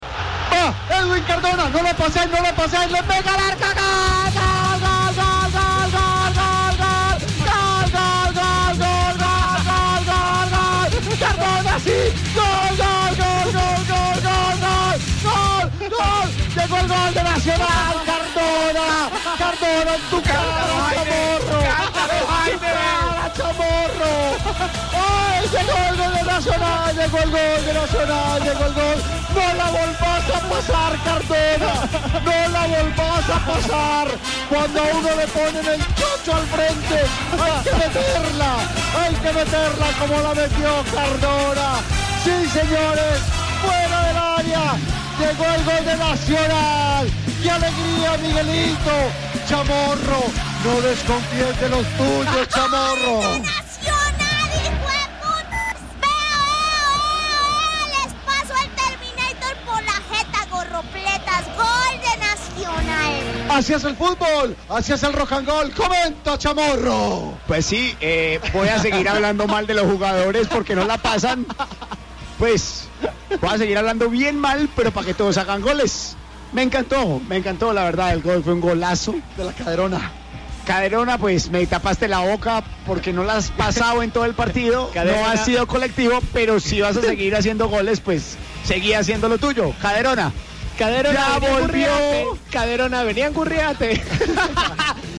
El único gol del partido lo marcó Edwin Cardona y así se vivió en el Rock and gol:
Gol-Nacional-Copa-libertadores.mp3